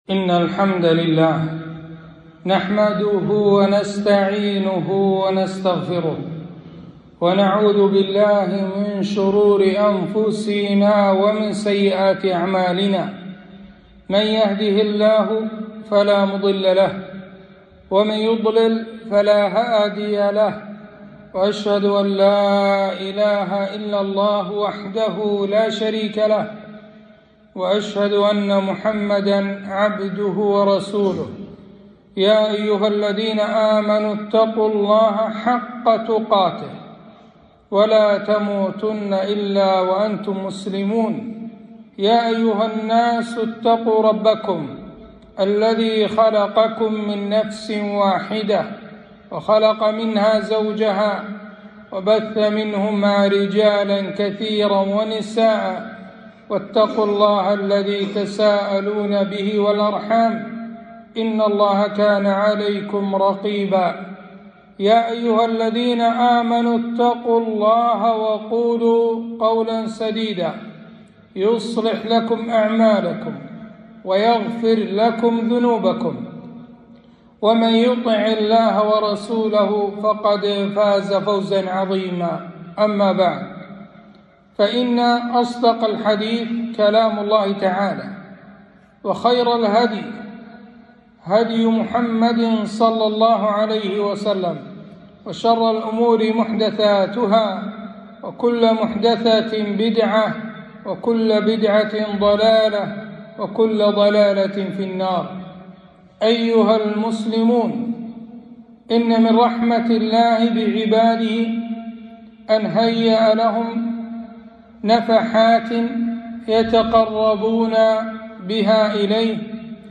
خطبة - شهر شعبان شهر الصيام والقرآن